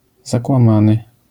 wymowa:
IPA[ˌzakwãˈmãnɨ], AS[zaku̯ãmãny], zjawiska fonetyczne: nazal.akc. pob.